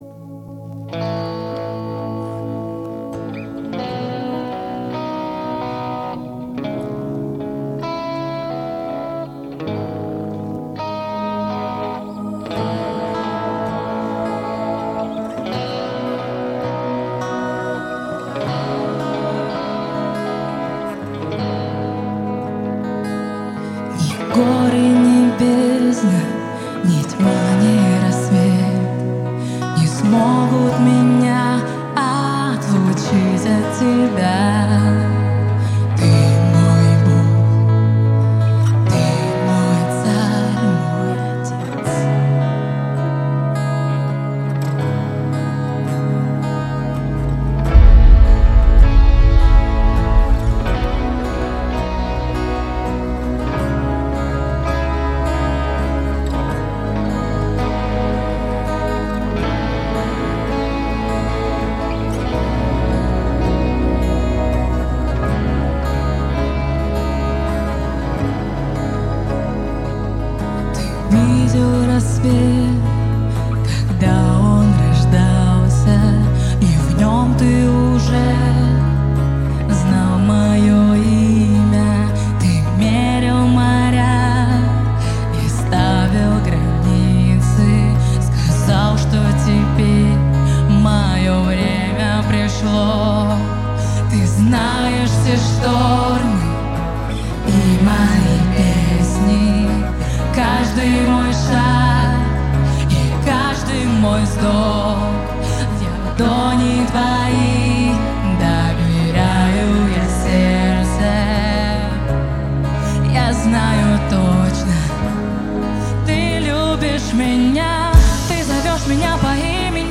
песня
55 просмотров 132 прослушивания 5 скачиваний BPM: 165